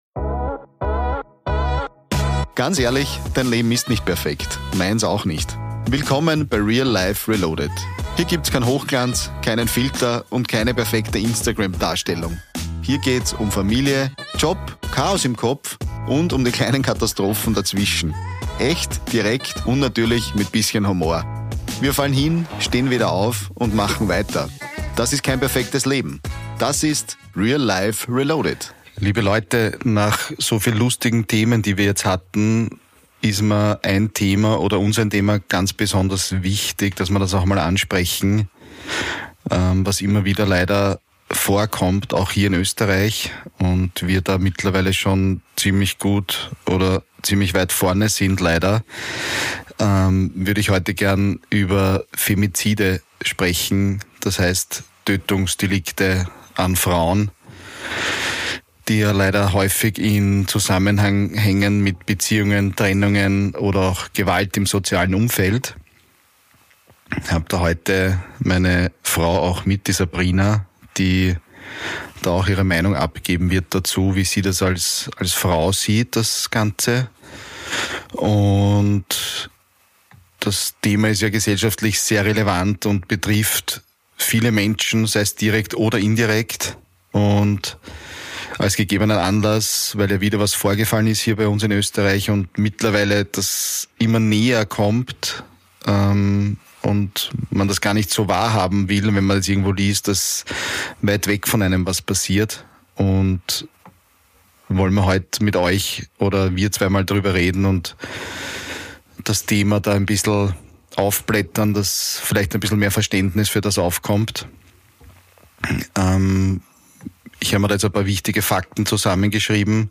Gemeinsam mit meiner Frau diskutiere ich über Ursachen, persönliche Gedanken und die Realität hinter den Schlagzeilen. Zwei Perspektiven, ehrliche Emotionen und eine Frage die bleibt: Warum ändert sich so wenig?